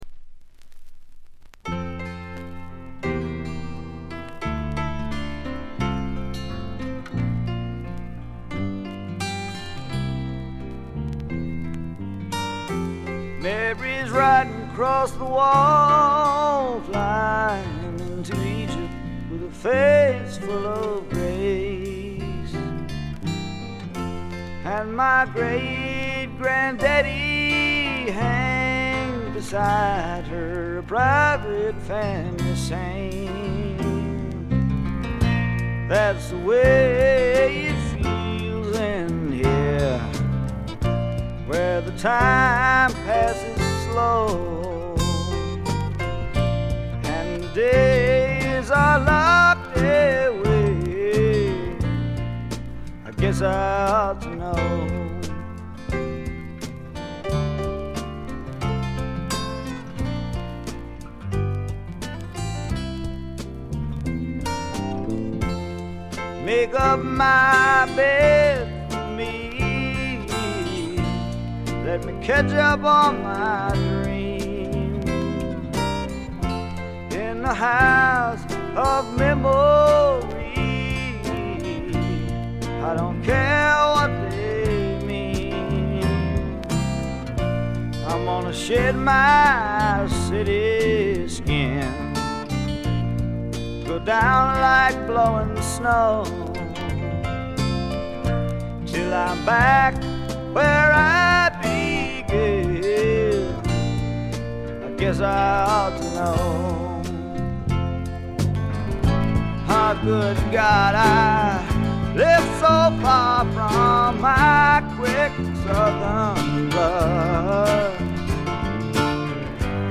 ごくわずかなノイズ感のみ。
聴くものの心をわしづかみにするような渋みのある深いヴォーカルは一度聴いたら忘れられません。
この時代はスワンプ的な色彩りの強い音作りが大流行していたわけですが、その最高峰に位置づけてもいいぐらいですよね。
試聴曲は現品からの取り込み音源です。